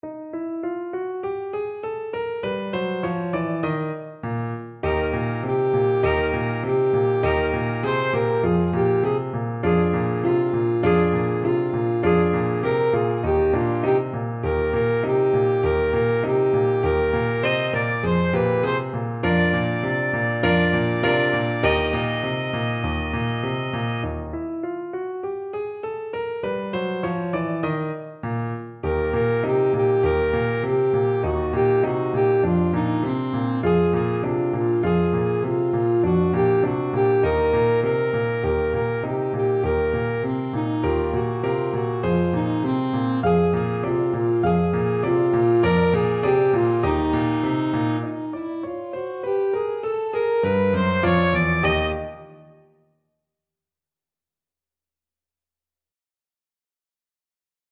Alto Saxophone version
Alto Saxophone
Energico
2/4 (View more 2/4 Music)
C5-Eb6
Pop (View more Pop Saxophone Music)